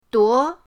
duo2.mp3